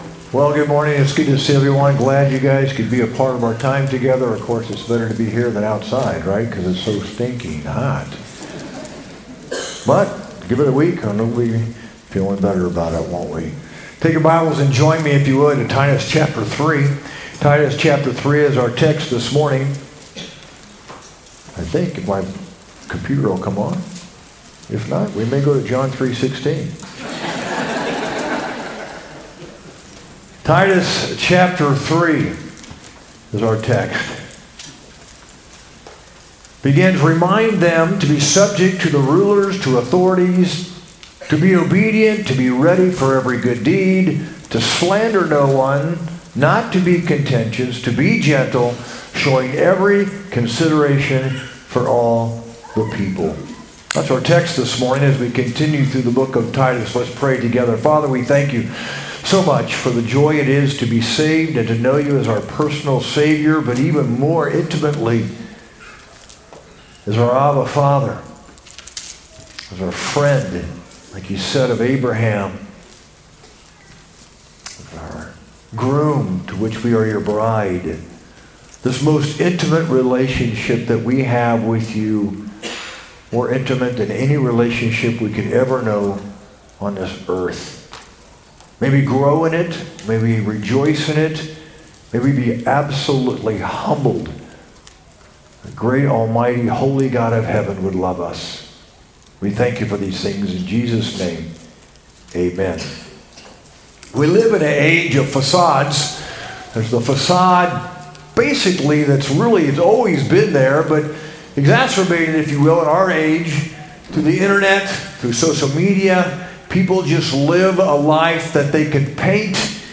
sermon-8-17-25.mp3